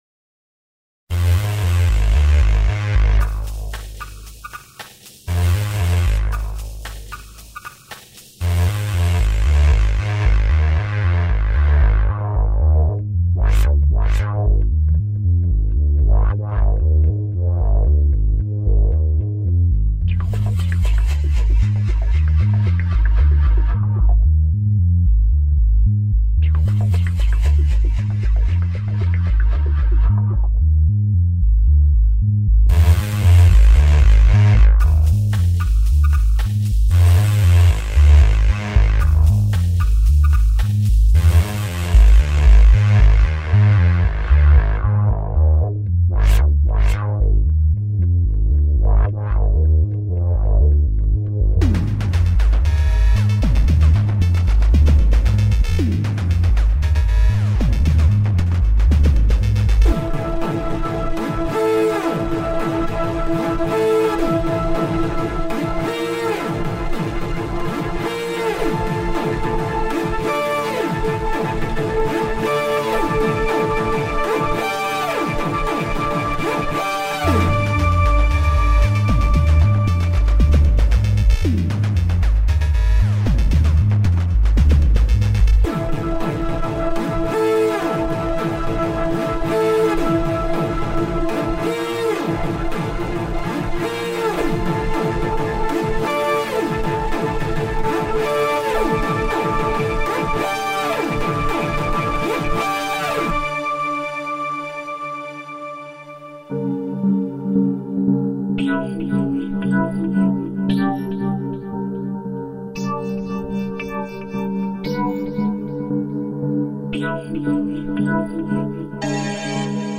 CINEMATIC MUSIC